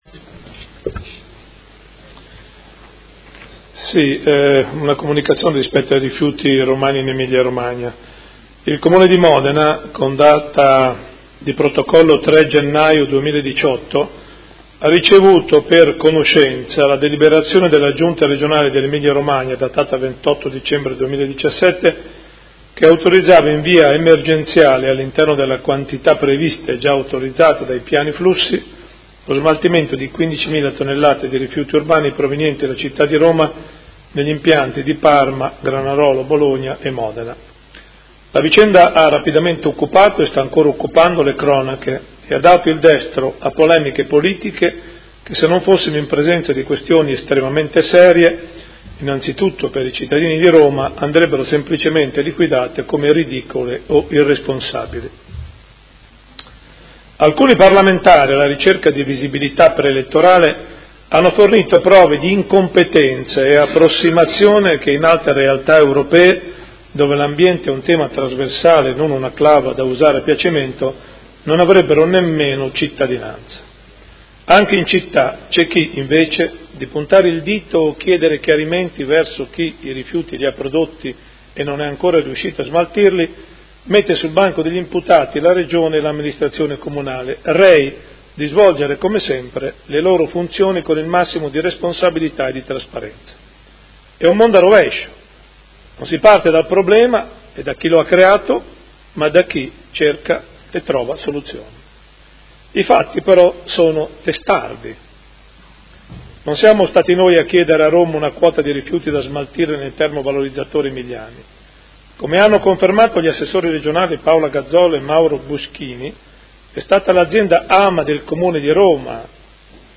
Seduta dell'11/01/2018 Comunicazione sullo smaltimento dei rifiuti di Roma.